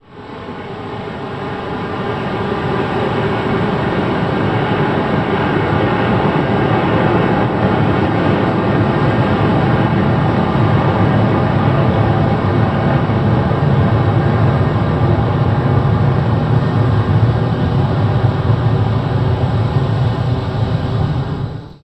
Avión volando en circulo a 200 metros de altura